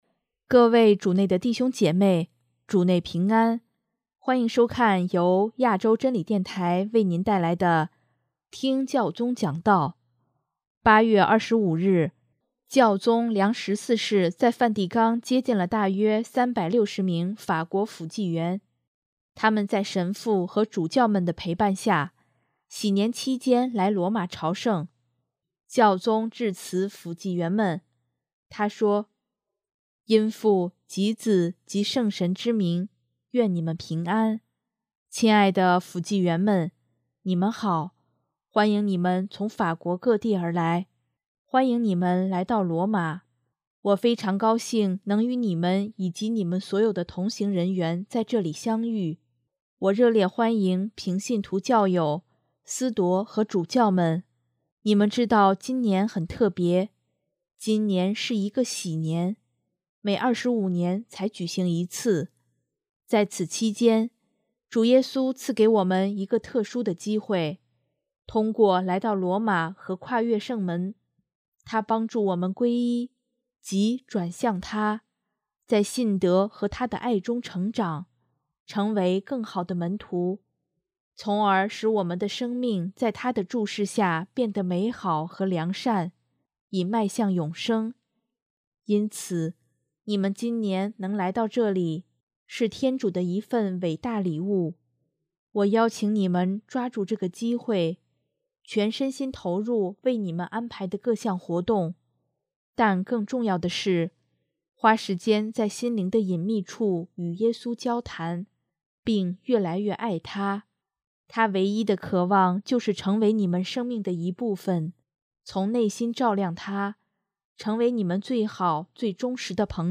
【听教宗讲道】|祂唯一的渴望就是成为我们生命的一部分
8月25日，教宗良十四世在梵蒂冈接见了大约360名法国辅祭员。他们在神父和主教们的陪伴下，禧年期间来罗马朝圣。